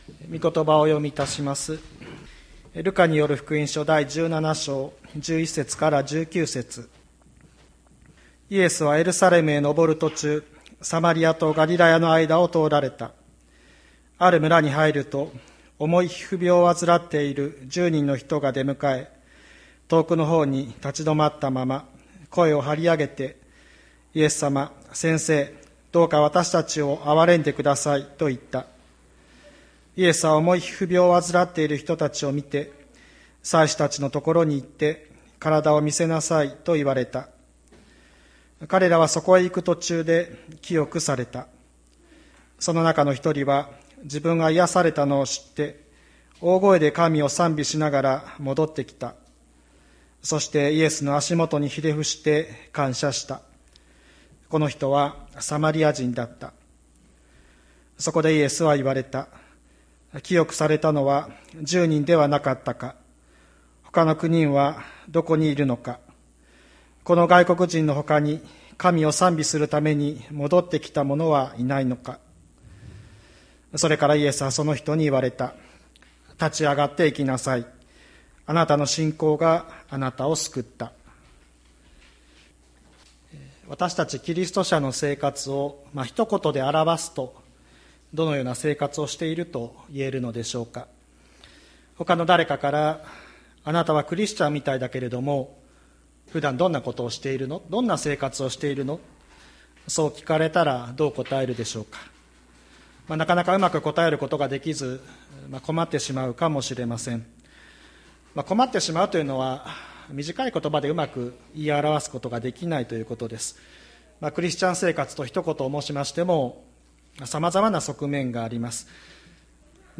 2021年05月09日朝の礼拝「いつも帰るべきところ」吹田市千里山のキリスト教会
千里山教会 2021年05月09日の礼拝メッセージ。